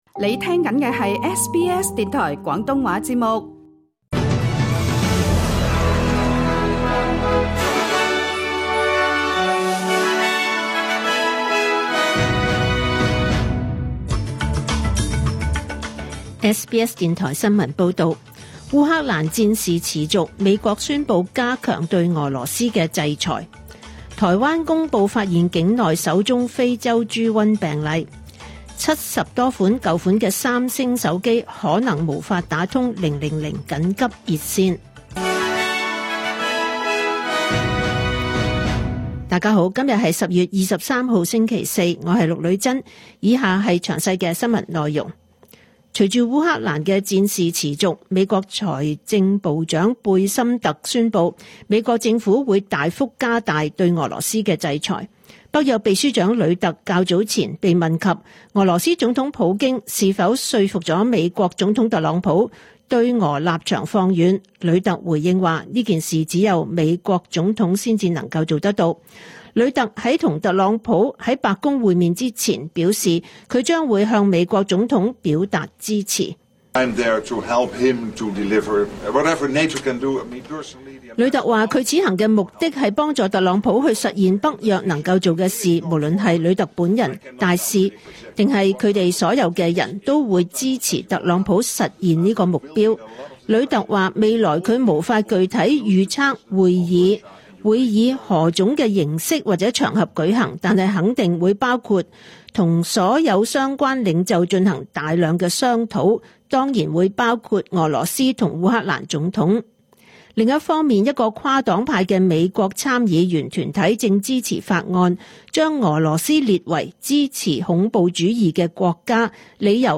2025 年 10 月 23 日 SBS 廣東話節目詳盡早晨新聞報道。